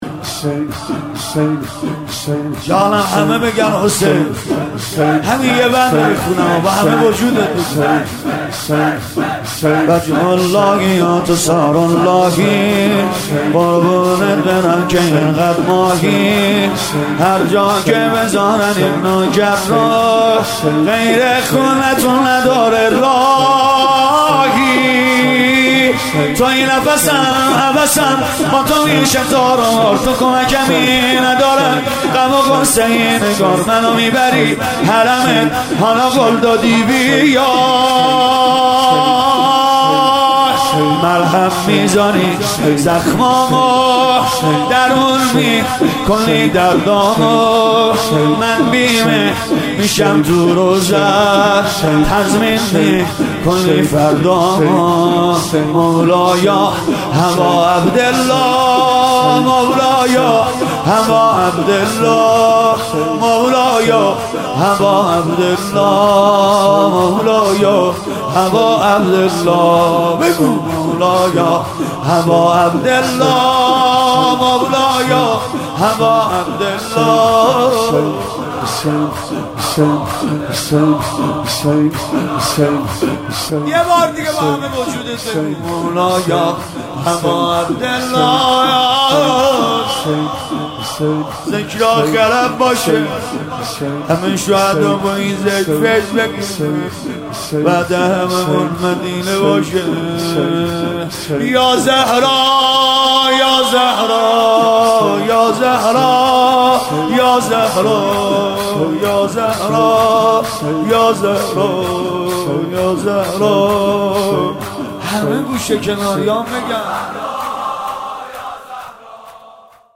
مداحی 9
شور